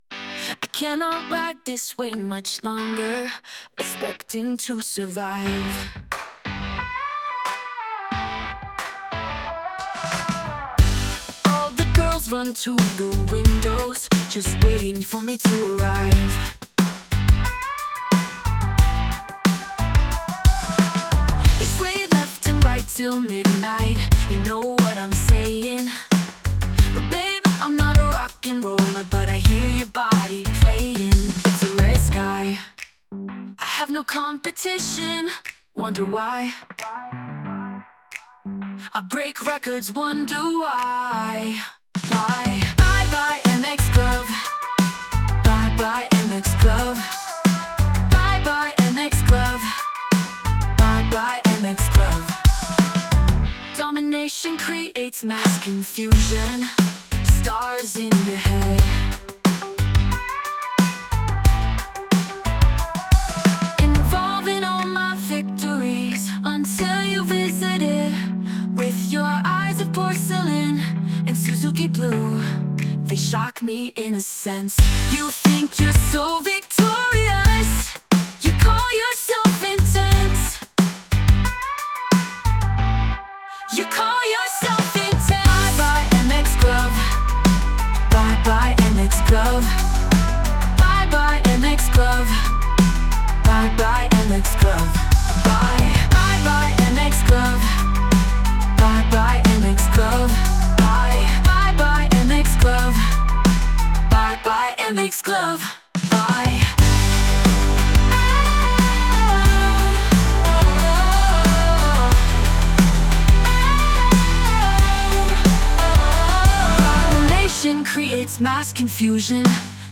pop: